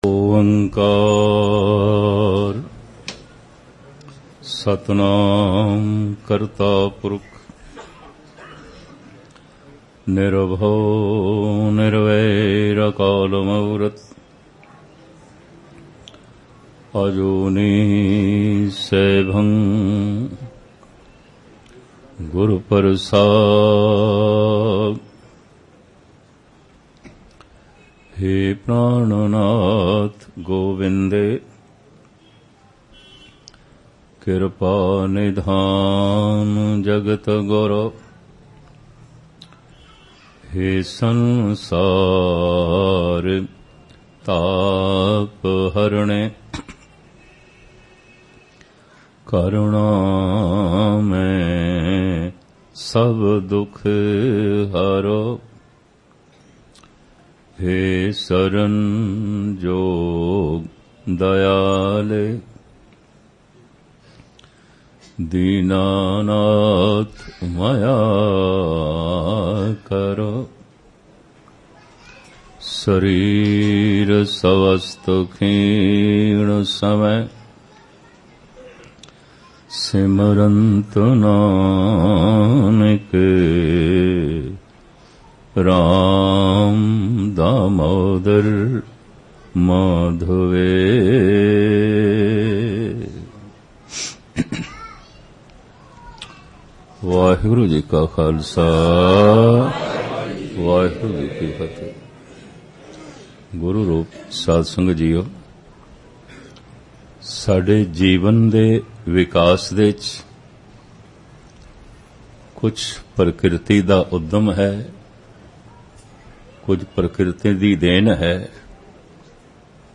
MITTE DHUND JAGG CHANAN HOYA Genre: Gurmat Vichar